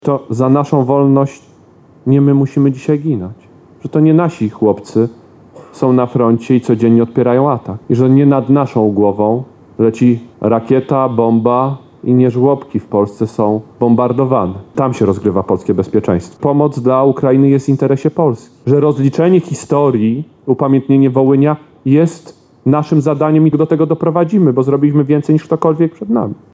– Bezpieczeństwo Polski rozgrywa się teraz na ukraińskim froncie – mówił w piątek (24.10) w Lublinie wicepremier i szef resortu obrony narodowej Władysław Kosiniak-Kamysz.